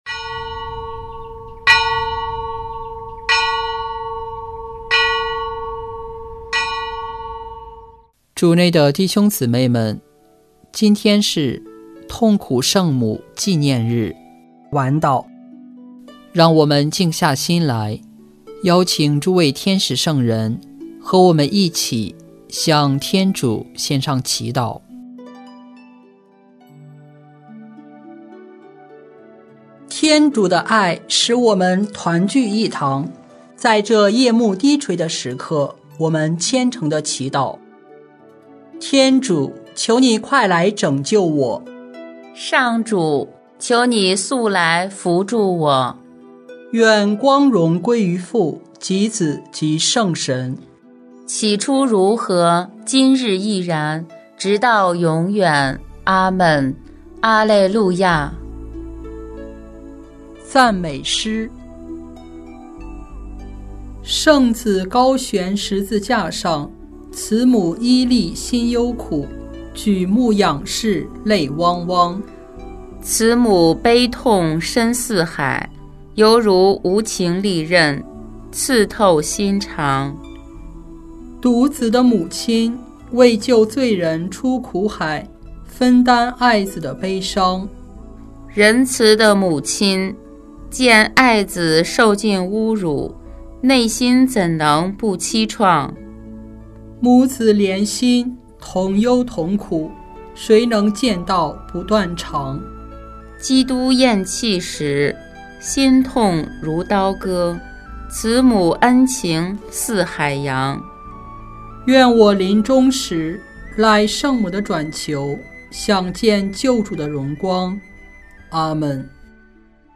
【每日礼赞】|9月15日痛苦圣母纪念日晚祷